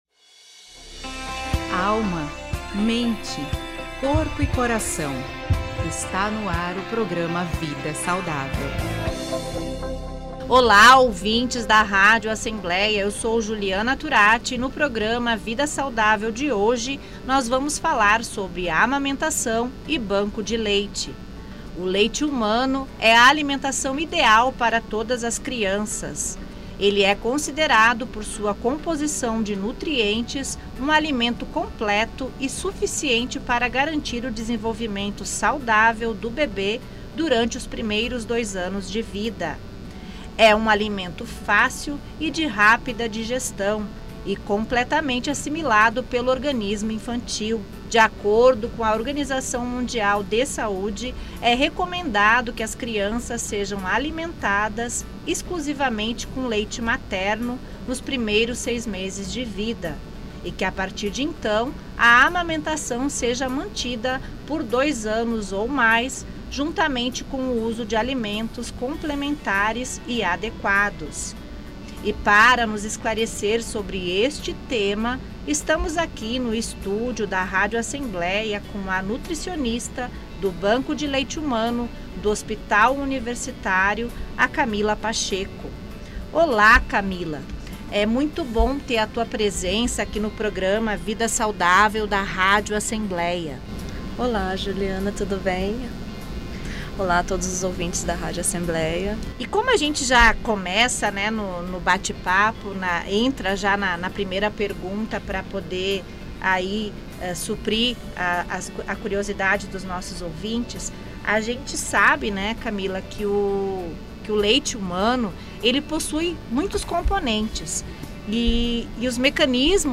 Download Locução e Produção